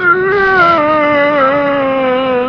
zombie_dies.ogg